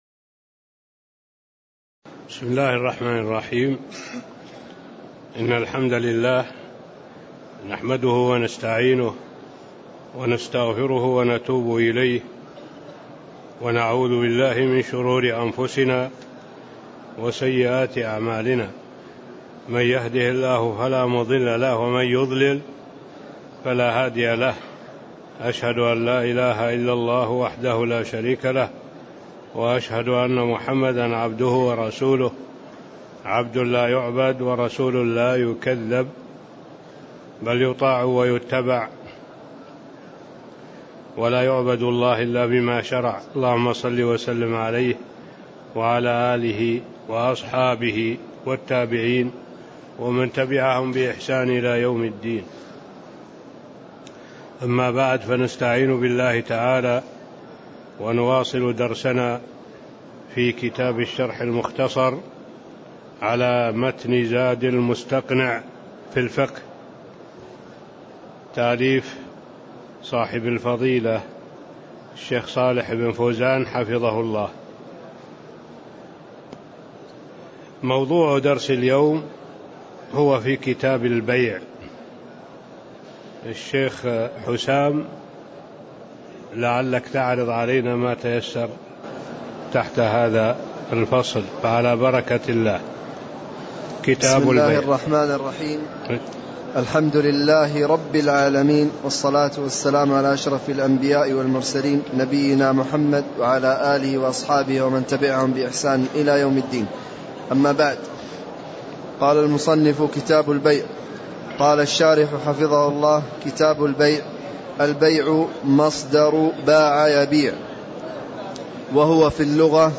تاريخ النشر ٨ محرم ١٤٣٥ هـ المكان: المسجد النبوي الشيخ: معالي الشيخ الدكتور صالح بن عبد الله العبود معالي الشيخ الدكتور صالح بن عبد الله العبود من قوله: والبيع أصله باع يبيع (01)